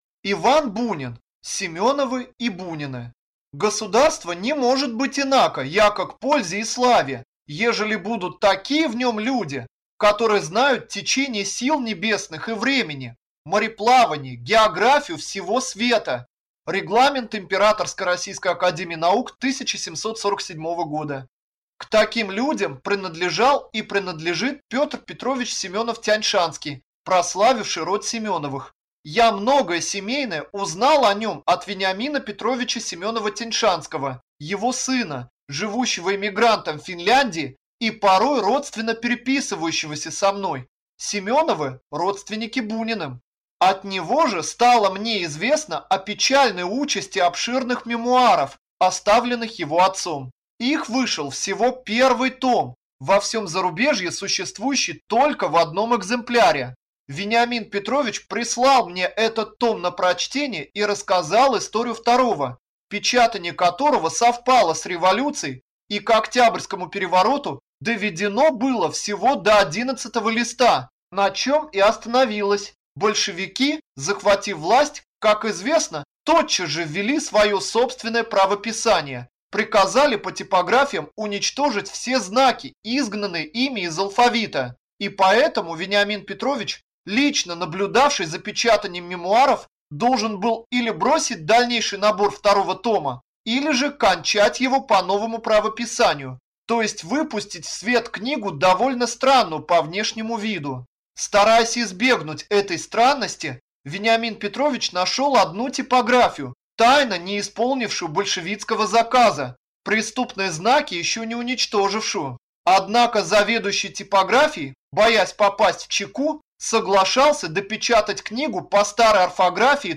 Аудиокнига Семеновы и Бунины | Библиотека аудиокниг